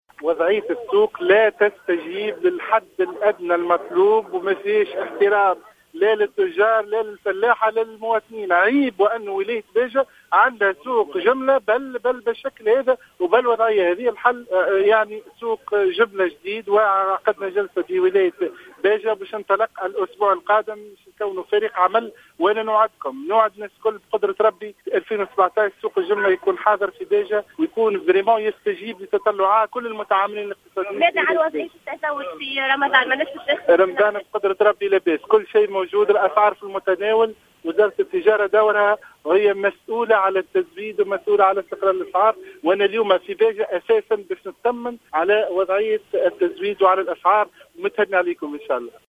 وقال حسن في تصريح